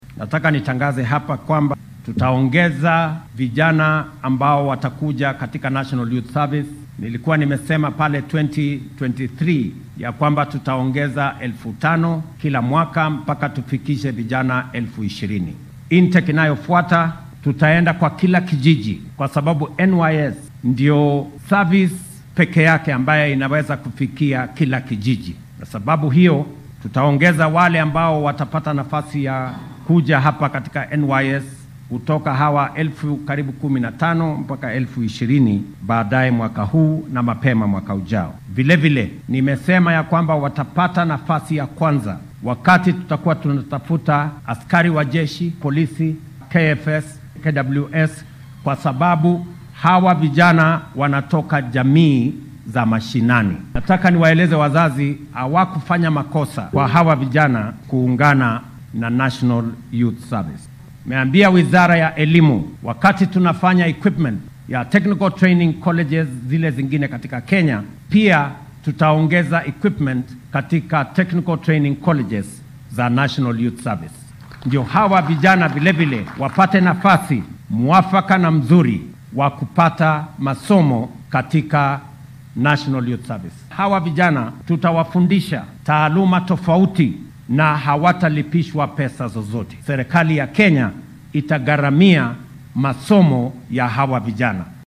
Arrintan ayuu maanta ka sheegay xaflad ay ku qalin jabinayeen dufcadii 88-aad ee dhallinyarada uu u soo dhamaaday tababarka adeegga qaran ee NYS oo lagu qabtay machadka adeeggan ee deegaanka Gilgil ee ismaamulka Nakuru.